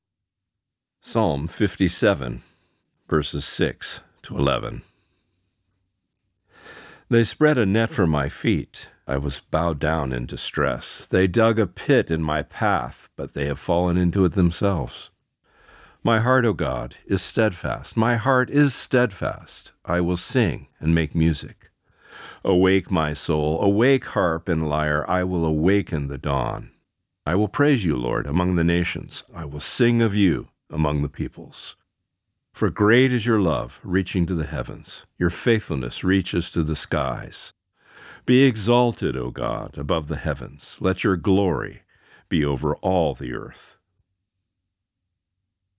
Reading: Psalm 57:6-11 (NIV)*